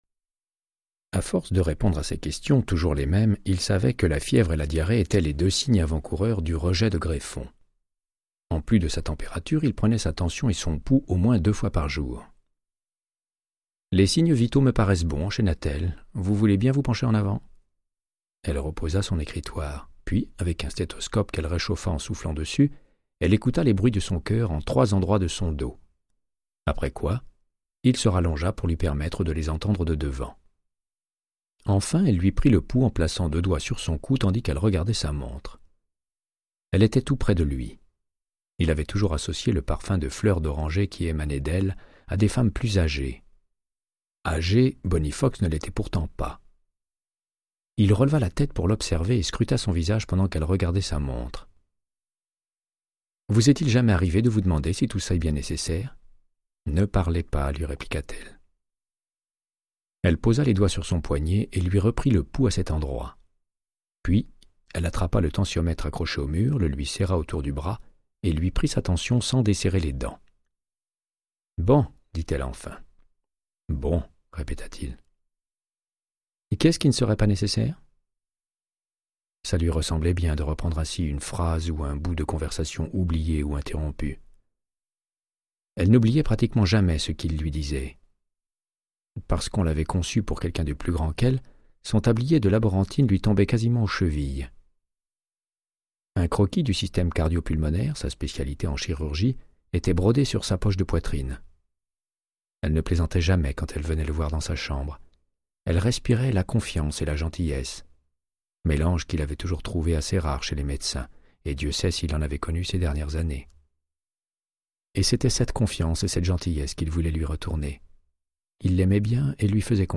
Audiobook = Créance de sang, de Michael Connellly - 05